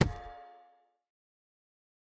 chiseled_bookshelf
insert_enchanted4.ogg